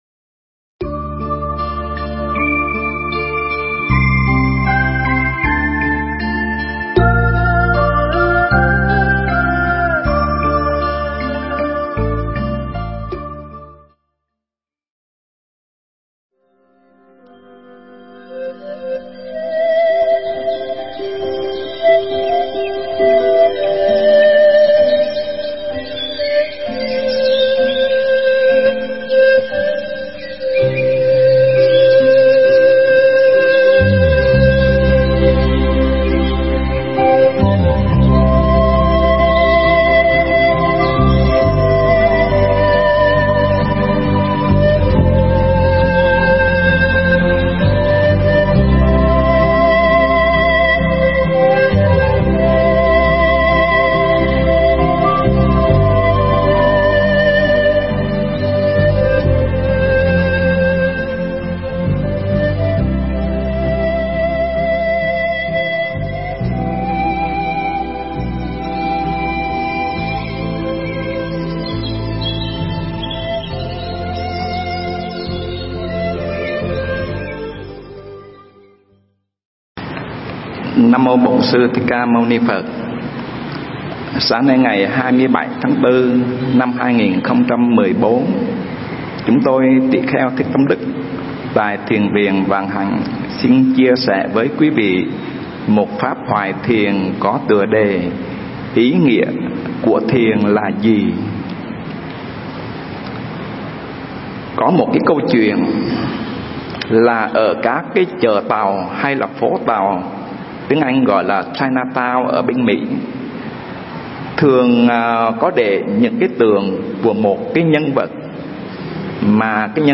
Mp3 Thuyết pháp Ý Nghĩa Của Thiền Là Gì